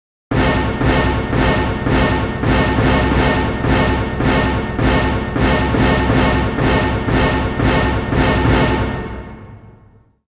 描述：不循环
Tag: 140 bpm Hip Hop Loops Fx Loops 1.73 MB wav Key : Unknown